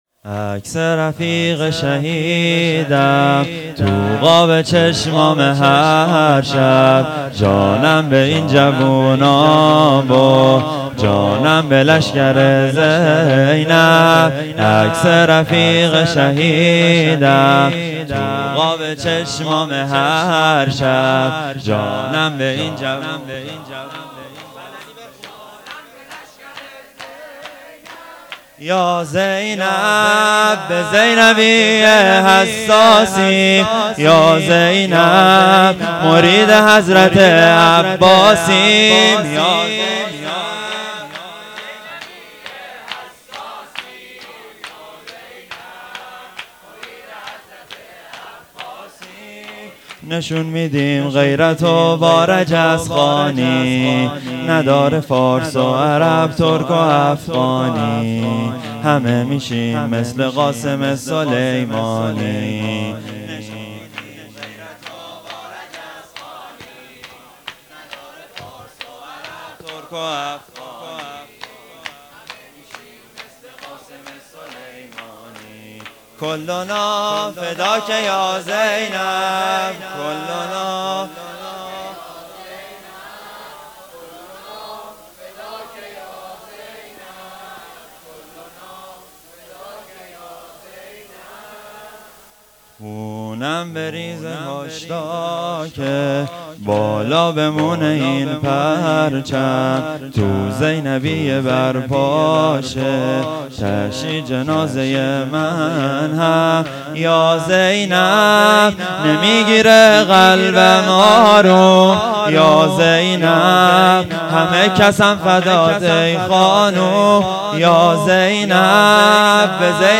شور | کُلُنا فِداکَ یازینب
صوت هیئت هفتگی 1400/7/8